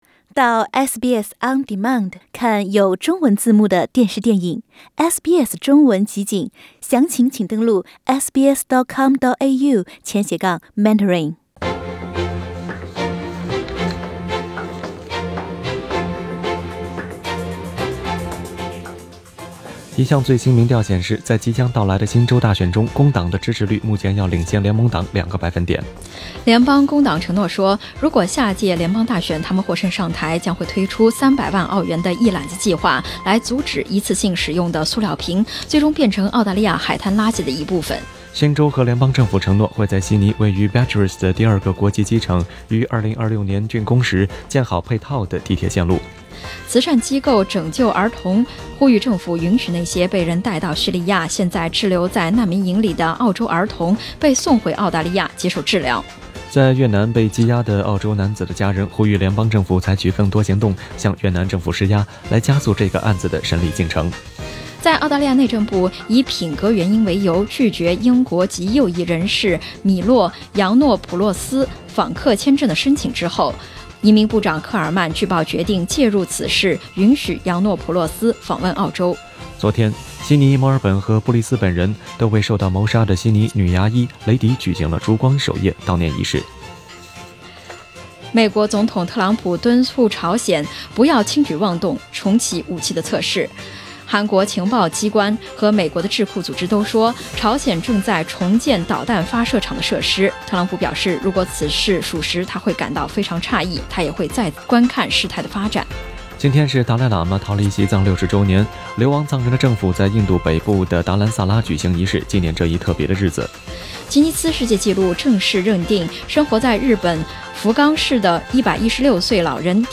SBS早新闻（3月10日）